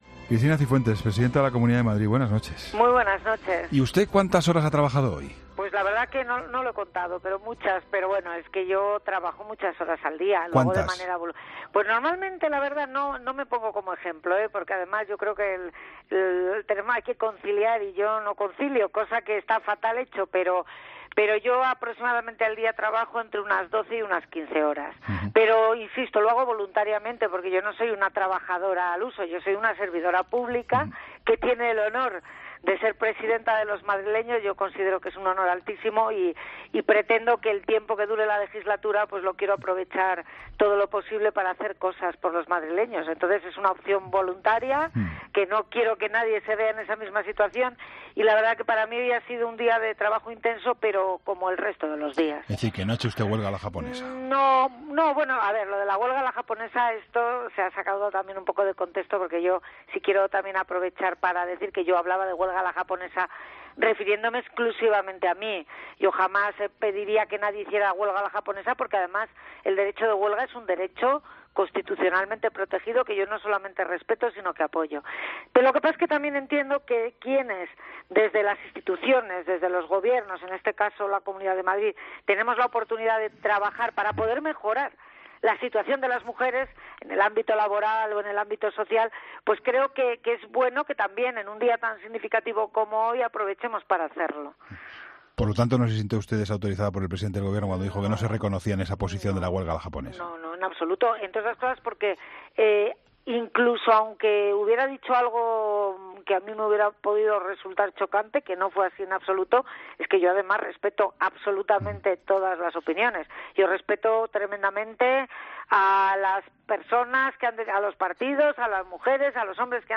ESCUCHA LA ENTREVISTA COMPLETA | Cristina Cifuentes en 'La Linterna'